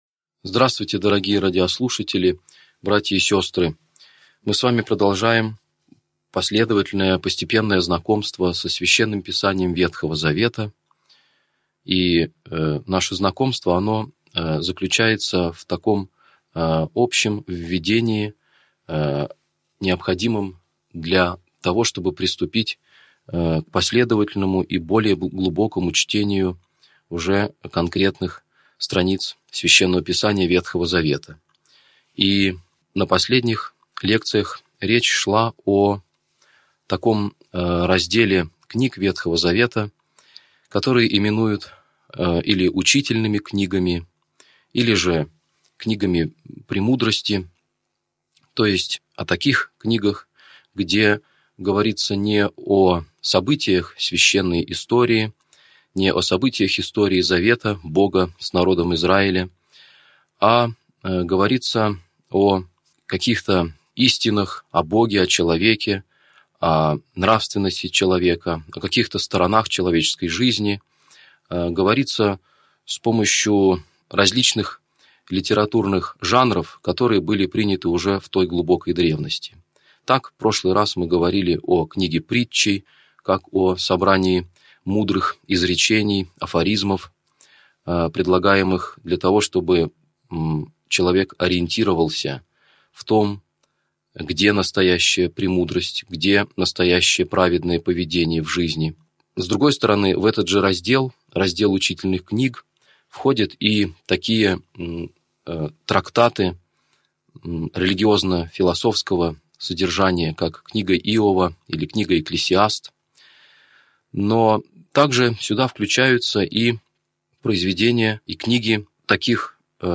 Аудиокнига Лекция 29. Песнь Песней | Библиотека аудиокниг